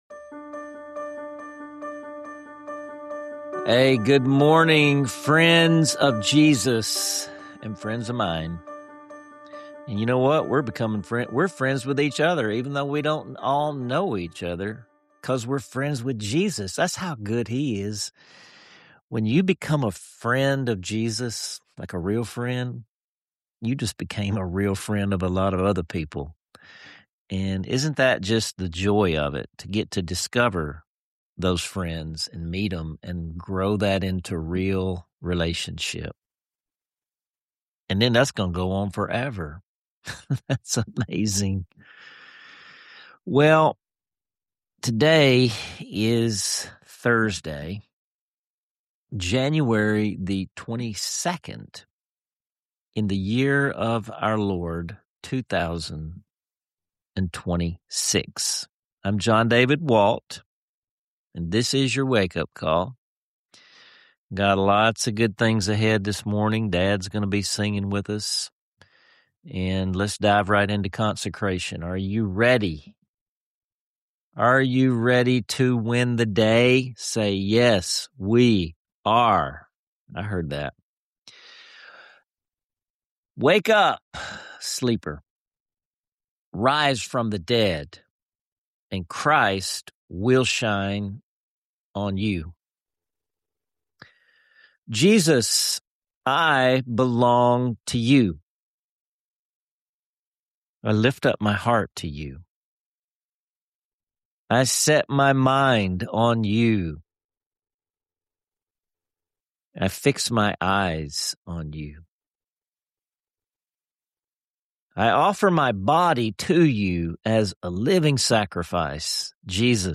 An uplifting discussion and hymn that remind us: when we accept who God made us to be, we find extraordinary freedom and the authority to love others well.